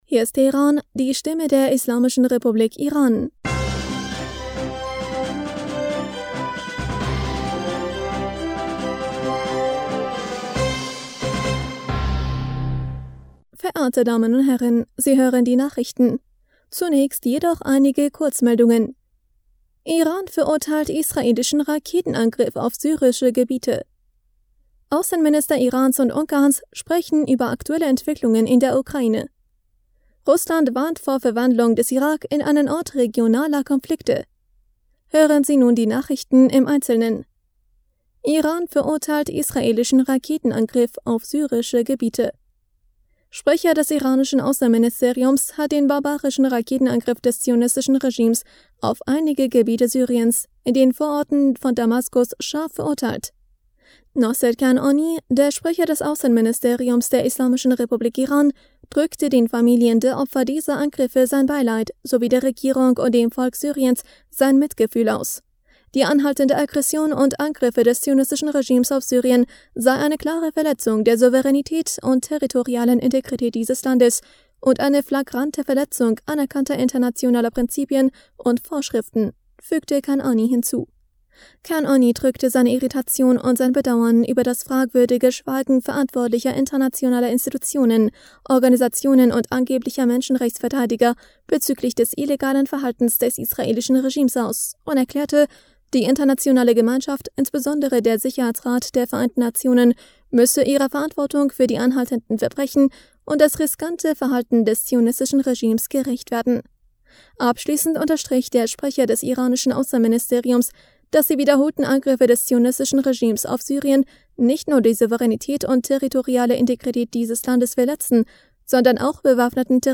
Nachrichten vom 23. Juli 2022